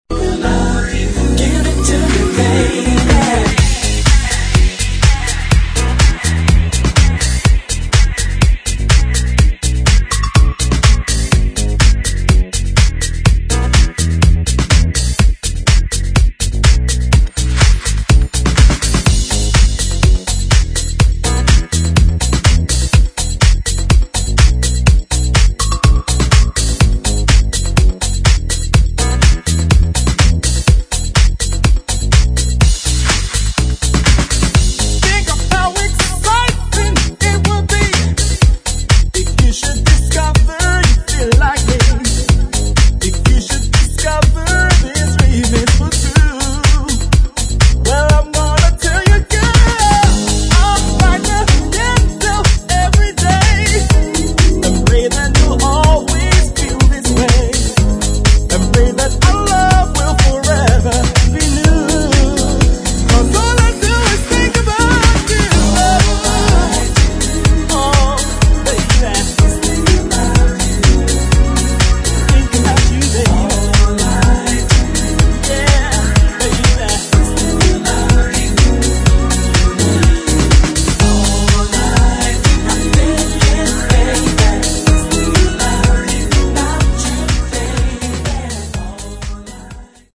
[ DISCO / HOUSE ]